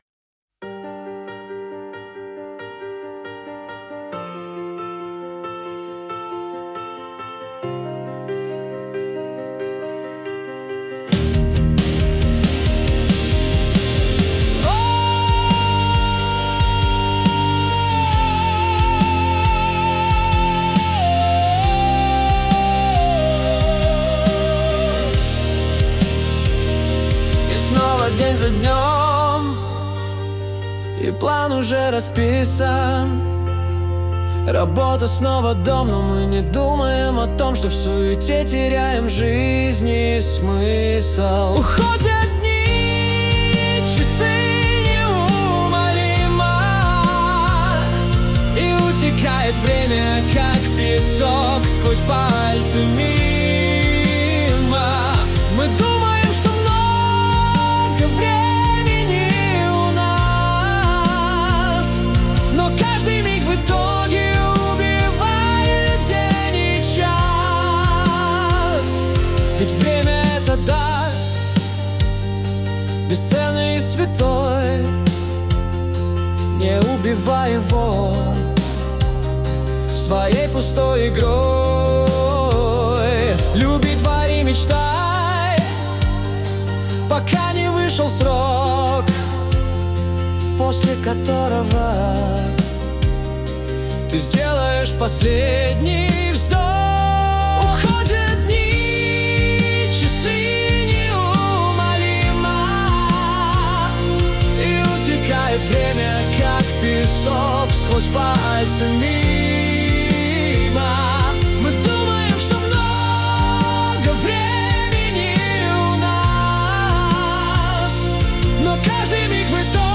226 просмотров 1201 прослушиваний 111 скачиваний BPM: 69